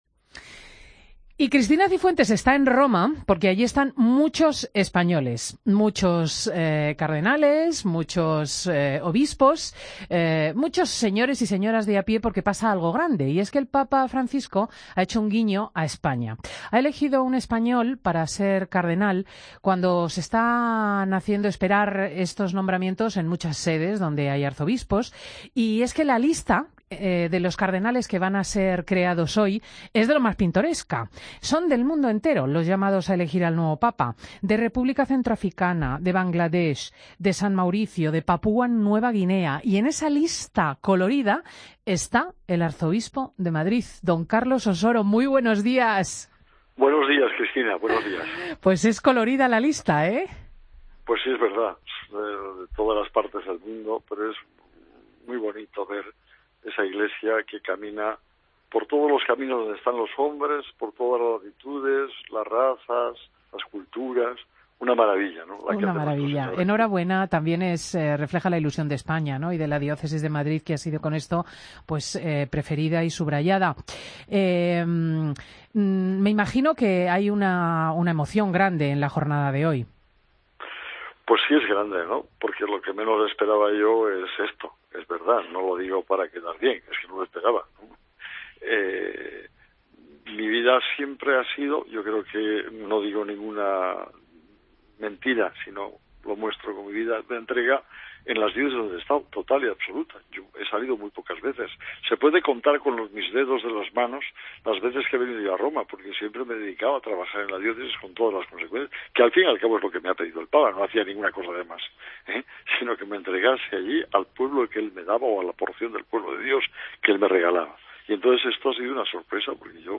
Escucha la entrevista al cardenal Carlos Osoro en Fin de Semana